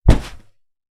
Punching Bag Rhythmic A.wav